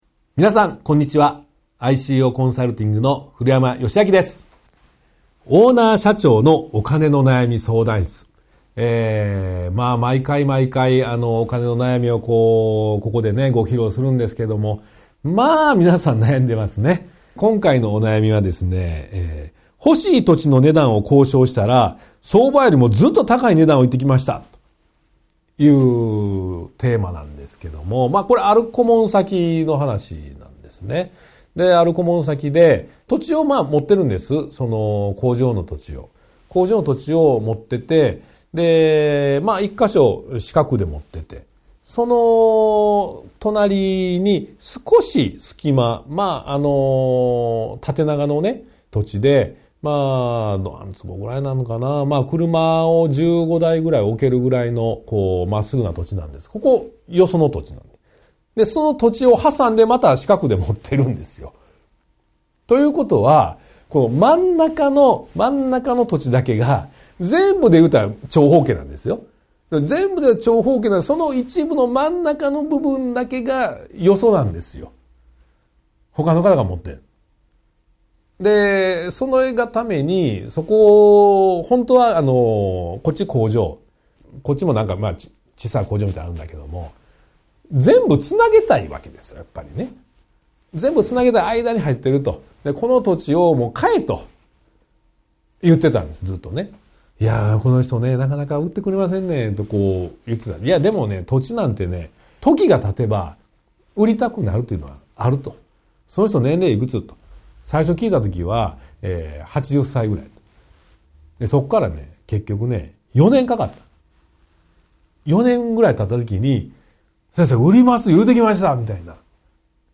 ワンポイント音声講座 相談3：欲しい土地の値段を交渉したら、相場よりもずっと高い値段を言ってきました！